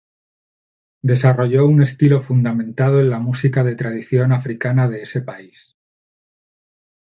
tra‧di‧ción
/tɾadiˈθjon/